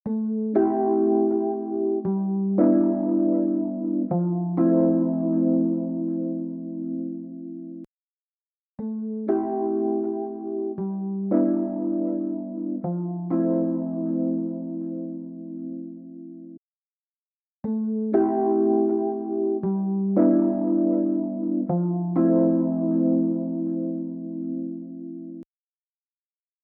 EChannel | Rhodes | Preset: Analog Juice